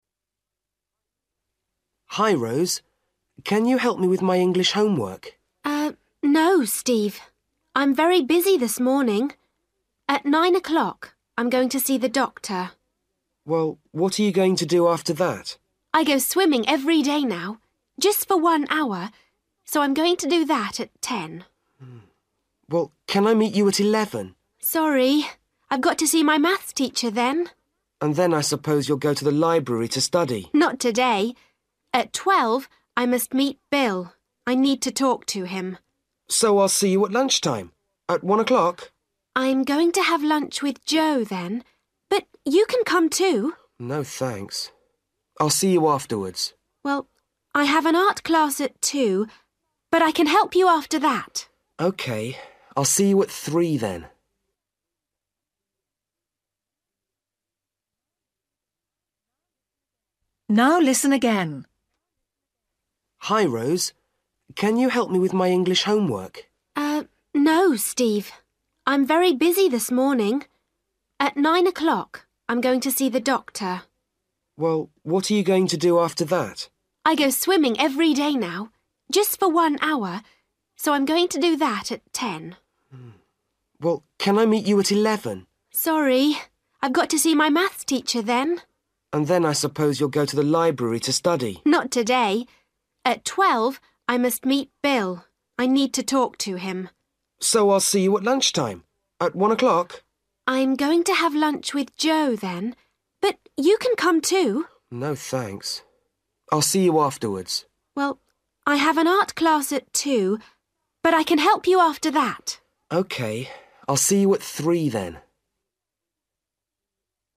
Luyện nghe trình độ A2